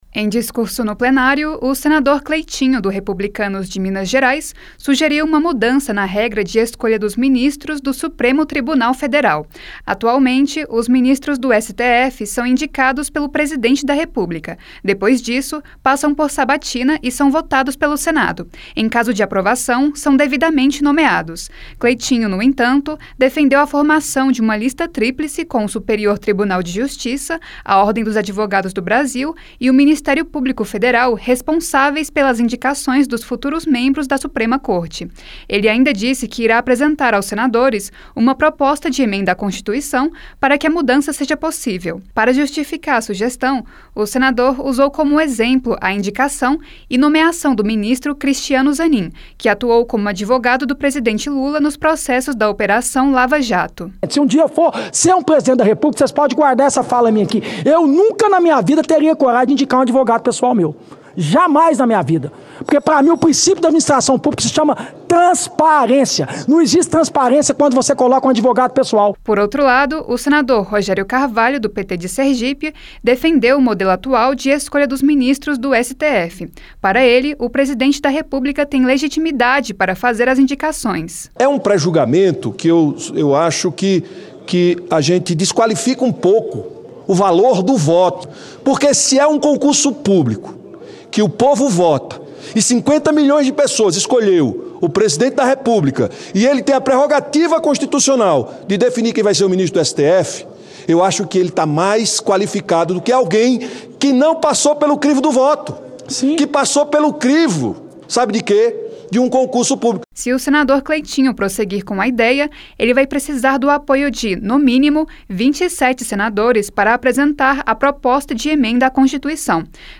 O senador Cleitinho (Republicanos-MG) sugeriu uma mudança na regra de escolha dos ministros do Supremo Tribunal Federal (STF) durante discurso no Plenário. Pela sugestão, em vez de indicação do Presidente da República, os ministros seriam indicados por uma lista tríplice formada pelo Superior Tribunal de Justiça, Ordem dos Advogados do Brasil e Ministério Público Federal.